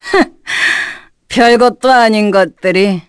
Nicky-Vox_Victory_kr.wav